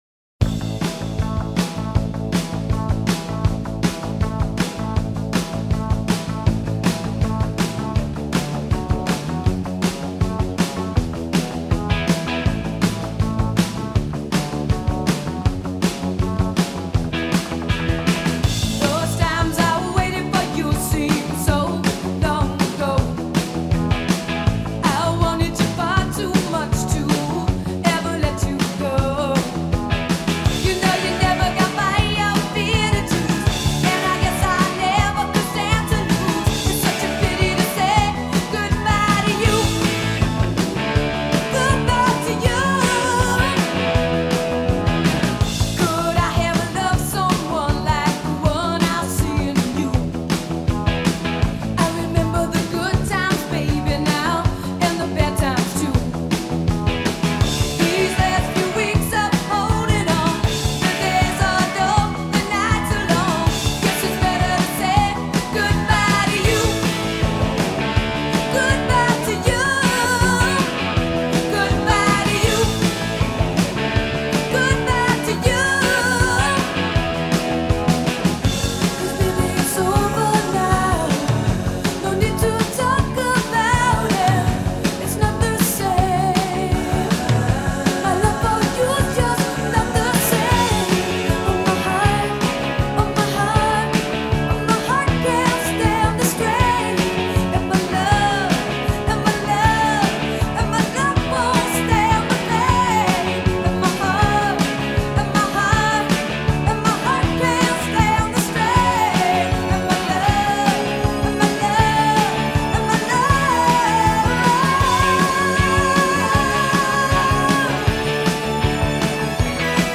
ridiculously bouncy